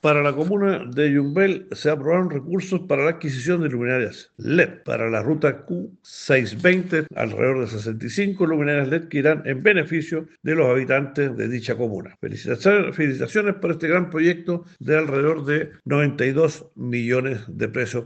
Por su parte, el Consejero Regional, Enrique Krause Lobos, detalló que son 55 luminarias las que están incluidas en el proyecto.